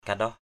/ka-ɗɔh/ (d.) vỏ (khô) = coquille. kandaoh baoh manuk k_Q<H _b<H mn~K vỏ trứng gà = coquille d’œuf de poule.